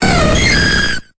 Cri de Mentali dans Pokémon Épée et Bouclier.